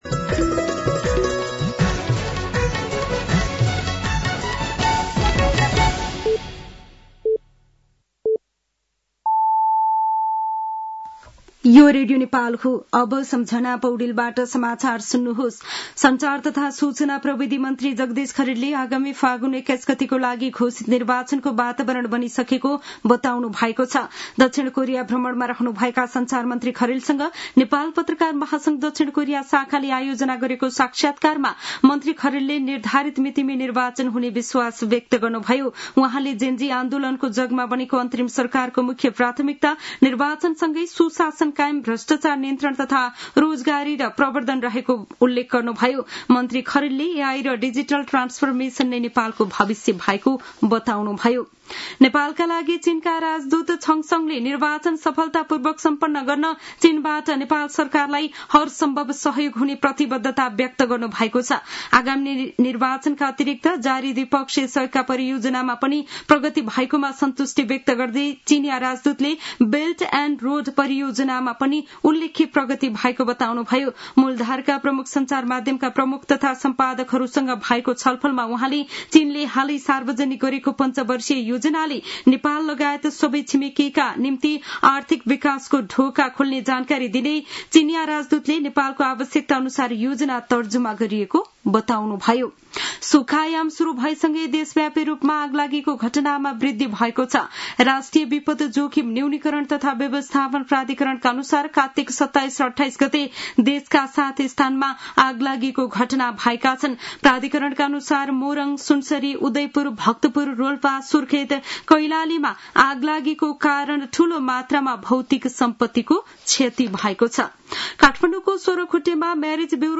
साँझ ५ बजेको नेपाली समाचार : २९ कार्तिक , २०८२
5.-pm-nepali-news-1-1.mp3